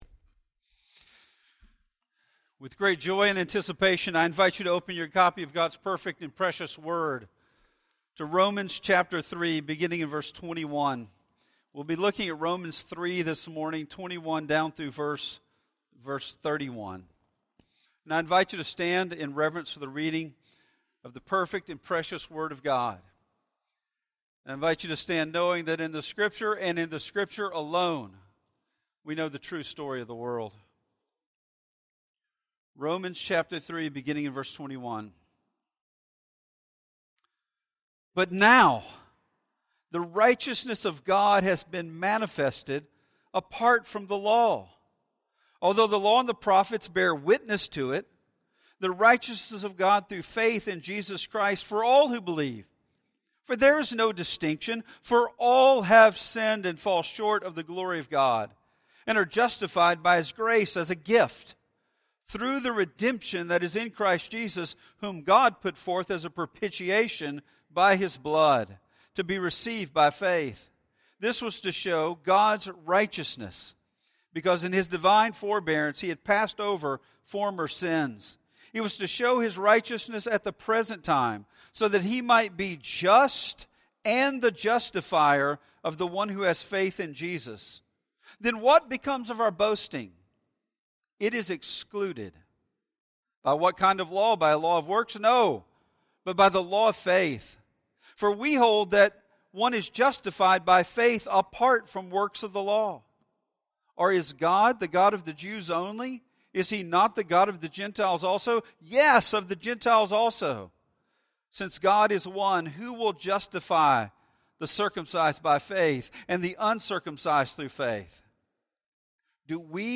In today's sermon we see that God's righteousness is the only ground for love, for justice, or salvation, and leaves no room to boast.